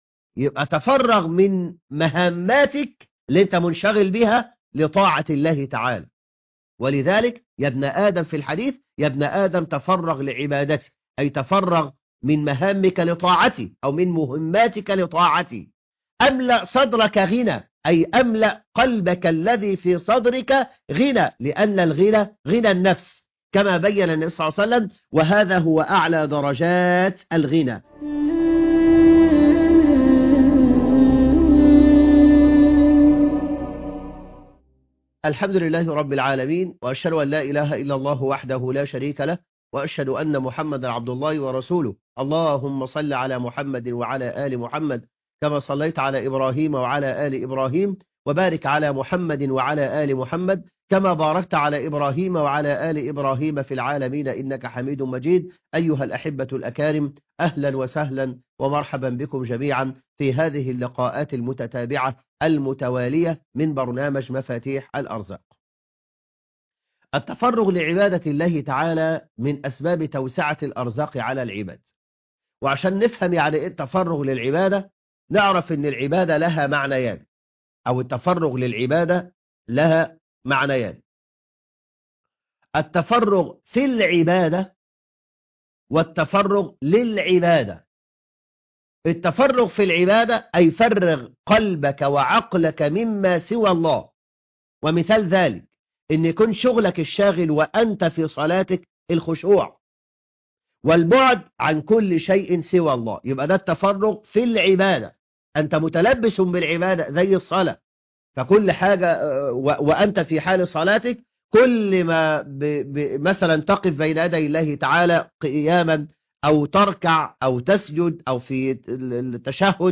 المقرأة - سورة يوسف ص 242